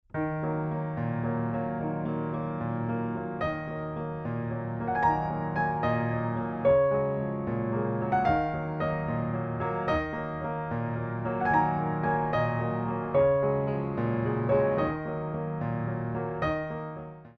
Petite Allegro
6/8 - 48 with repeat